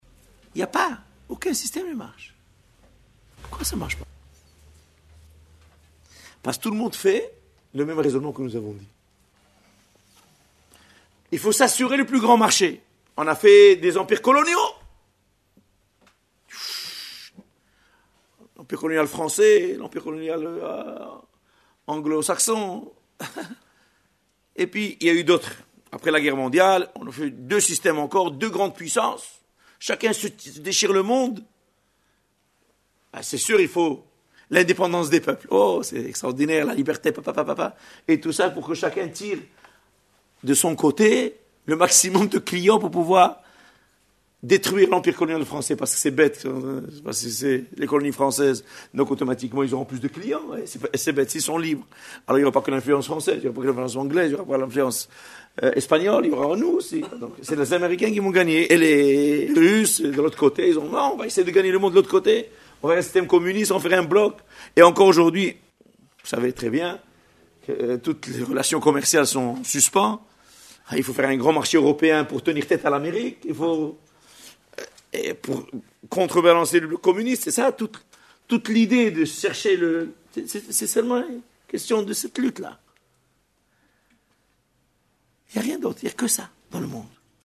Exposé magistral